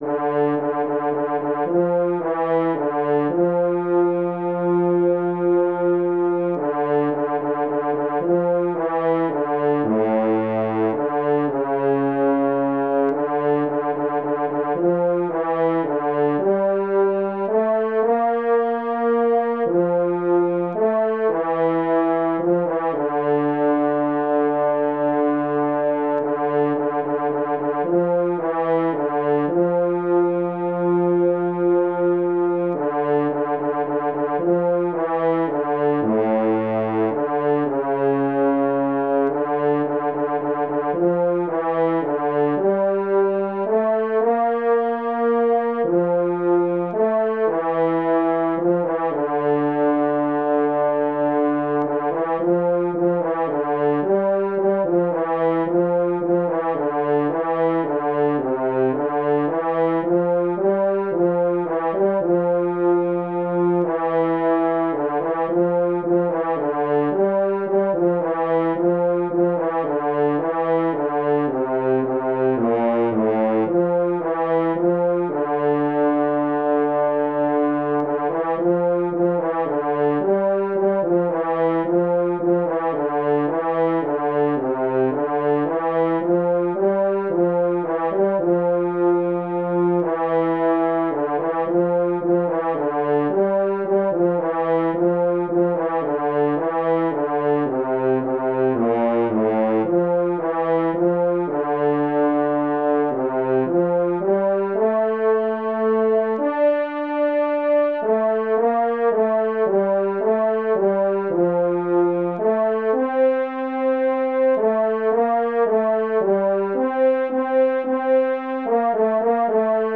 Jewish Chassidich Niggun
D minor ♩= 110 bpm